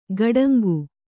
♪ gaḍaŋgu